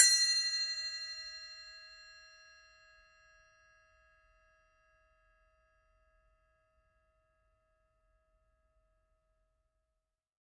5.5" Cup Chime
Die heutige 2002 ist auf dem Fundament der originalen klassischen Cymbals errichtet, und um moderne Sounds für zeitgenössische progressive Music bereichert.
5.5_cup_chime_stroke.mp3